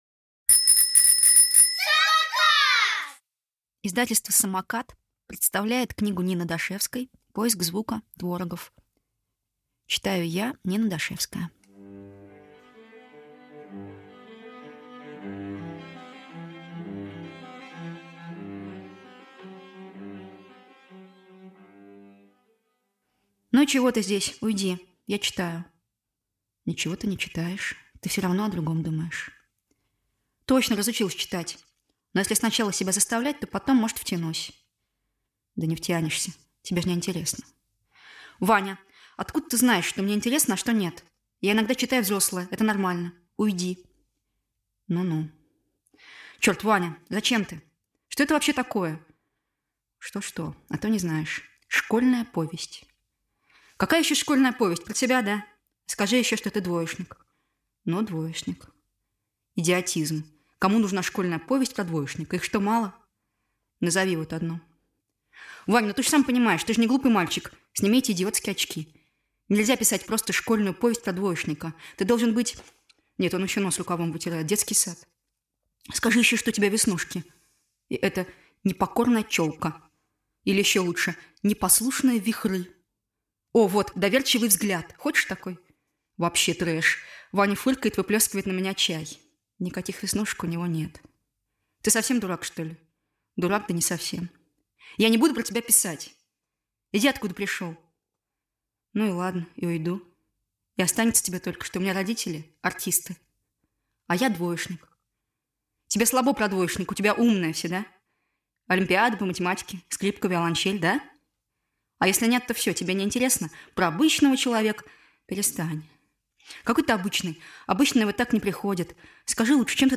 Аудиокнига Поиск звука. Творогов | Библиотека аудиокниг